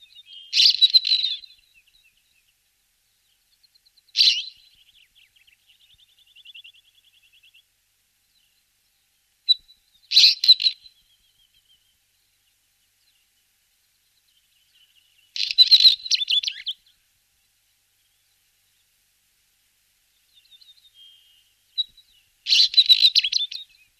Białorzytka - Oenanthe oenanthe
głosy